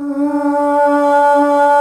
AAAAH   D.wav